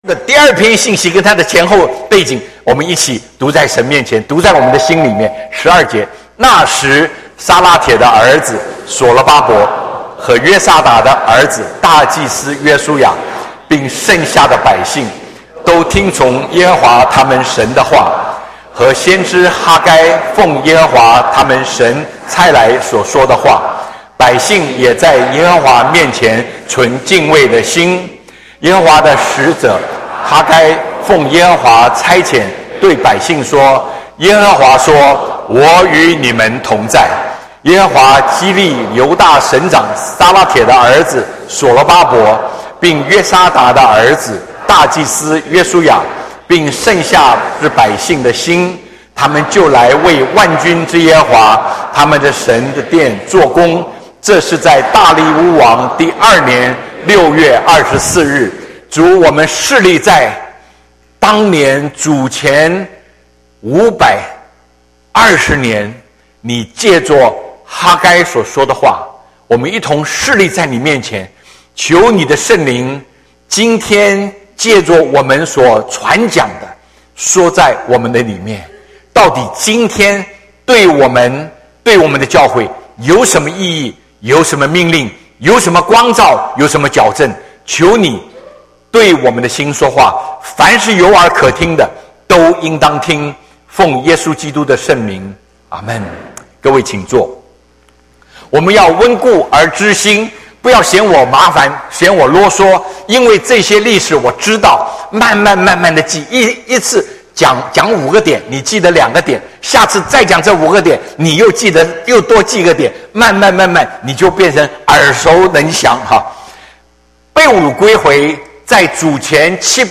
Sermon | CBCGB